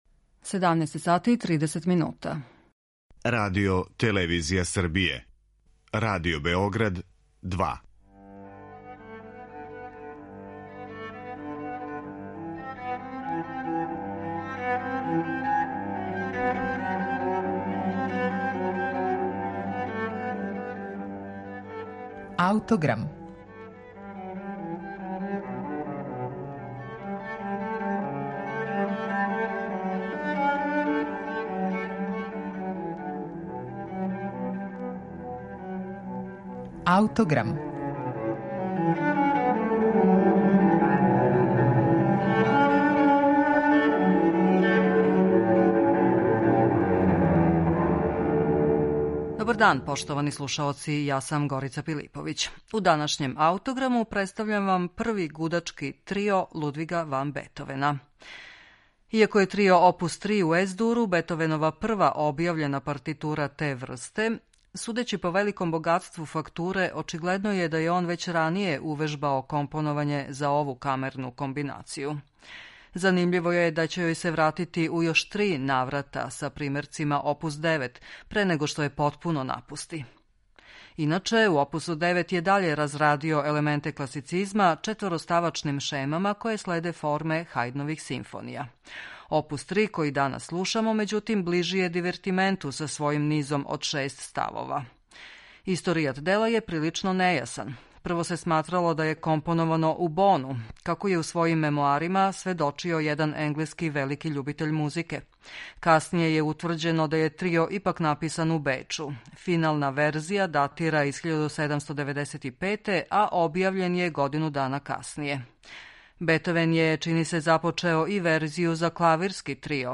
Гудачки трио опус 3 у Ес-дуру Лудвига ван Бетовена не само да је његова прва композиција те врсте, већ је и прва објављена партитура.